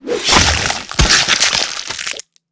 slash.wav